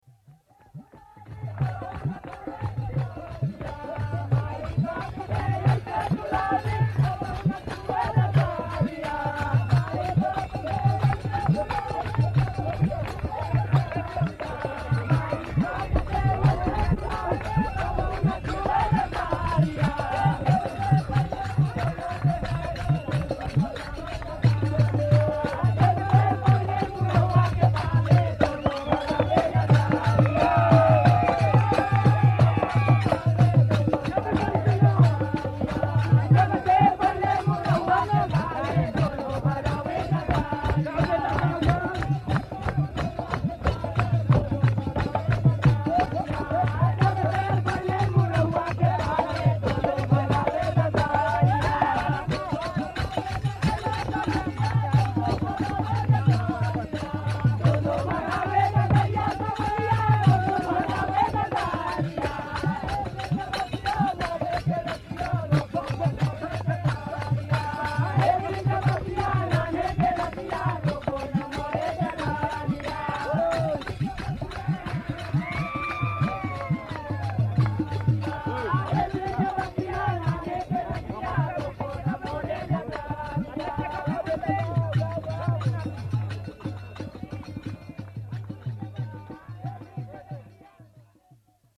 Inspelningarna är gjorda på fältet
Chowtal  Sång vid den hindiuska högtiden phagwa eller holi.